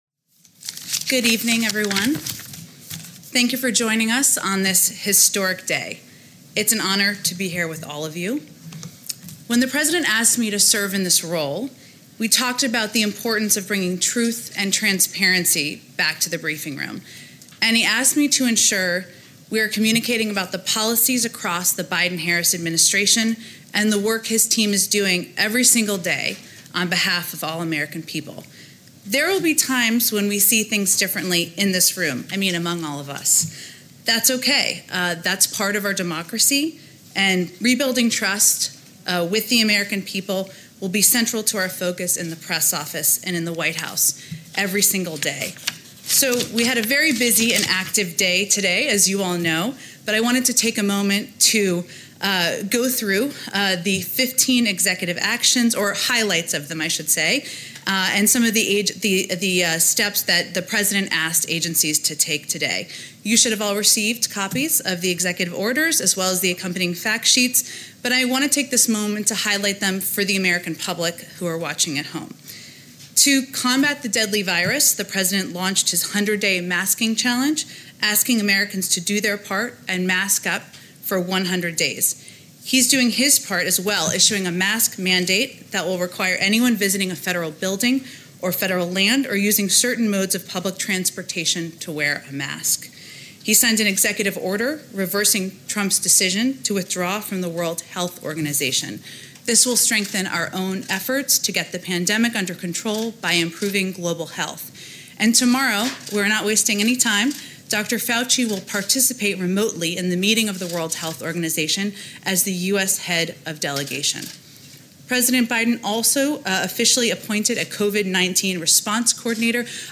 Jen Psaki - First White House Press Briefing (text-audio-video)
jenpsakifirstwhpresserARXE.mp3